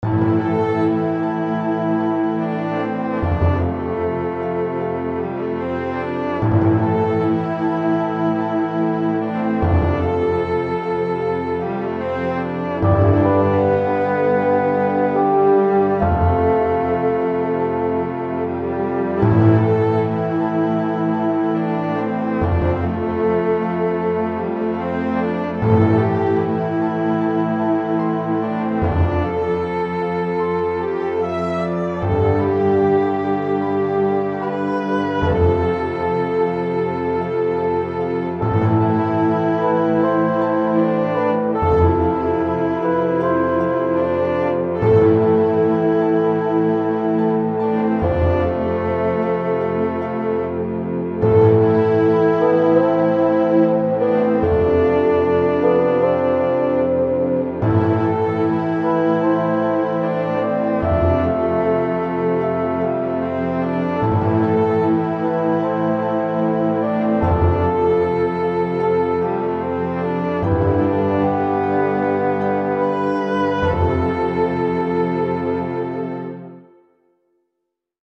Einer meiner frühen Versuche, ein orchestrales Stück zu schreiben und aufzunehmenen. Es handelt sich hierbei um einen frühmittelalterlichen Trauermarsch.
Aufgenommen mit XG-Works und einem Yamaha S30.